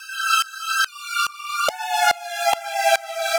Index of /musicradar/uk-garage-samples/142bpm Lines n Loops/Synths
GA_SacherPad142A-02.wav